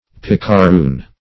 Search Result for " picaroon" : The Collaborative International Dictionary of English v.0.48: Picaroon \Pic`a*roon"\, n. [Sp. picaron, aug. of picaro roguish, n., a rogue.]